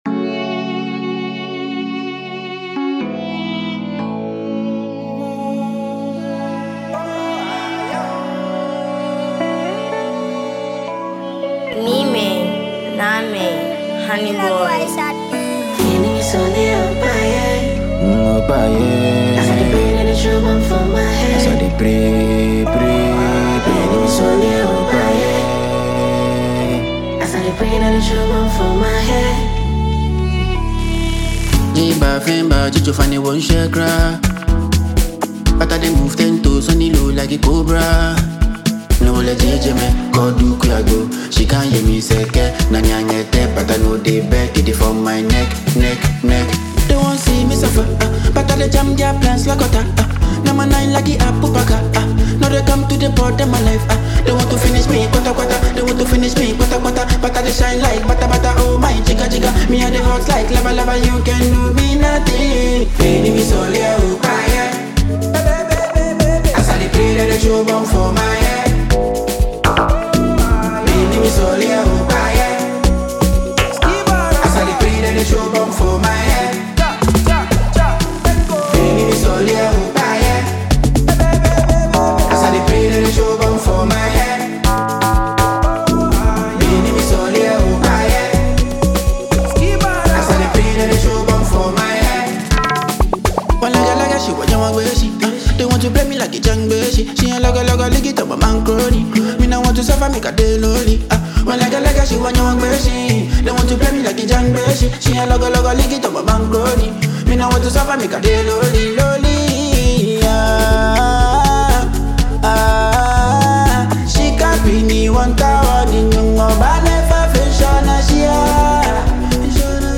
Genre: Afrobeat / Love